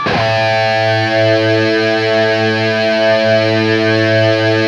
LEAD A 1 LP.wav